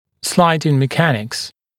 [‘slaɪdɪŋ mɪ’kænɪks][‘слайдин ми’кэникс]механика скольжения